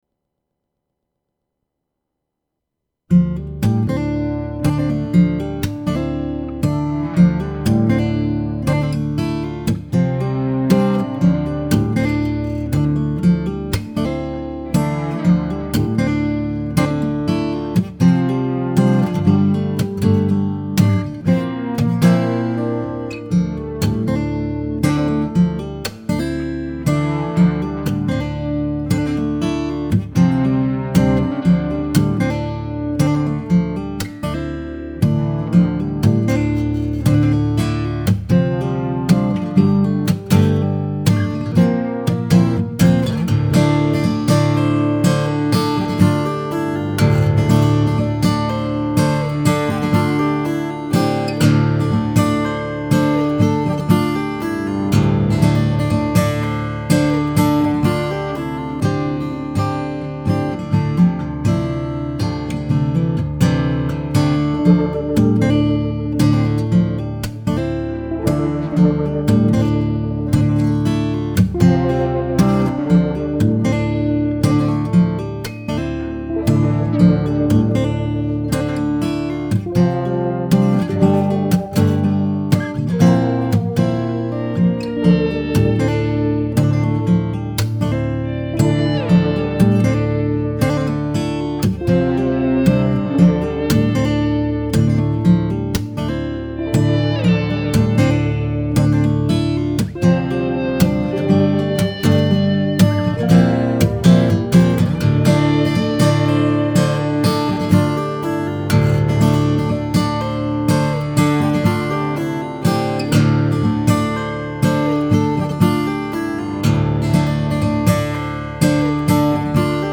added electric guitar tracks